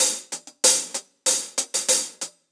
Index of /musicradar/ultimate-hihat-samples/95bpm
UHH_AcoustiHatB_95-05.wav